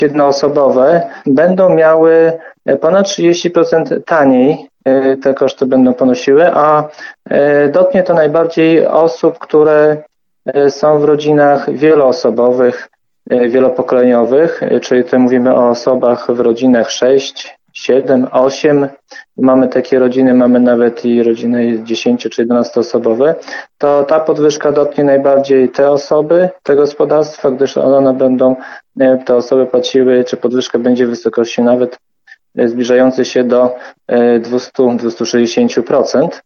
O szczegółach mówi Zbigniew Mackiewicz, wójt gminy Suwałki.